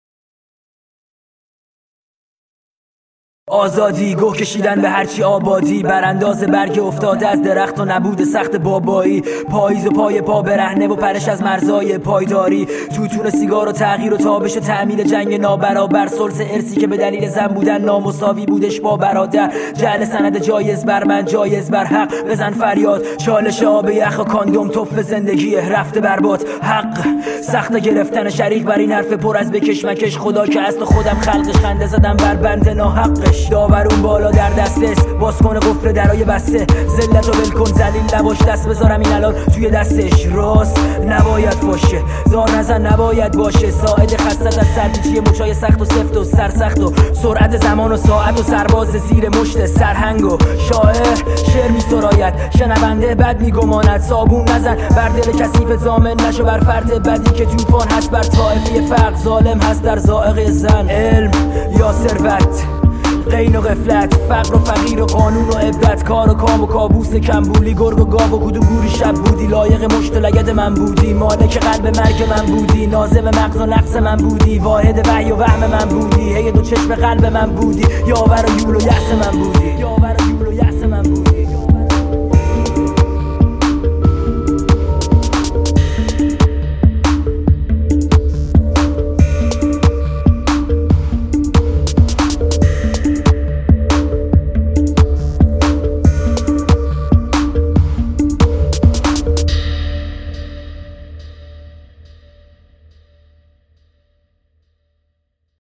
رپ کردن به ترتیب حروف الفبا
دانلود آهنگ های جدید رپ فارسی های جدید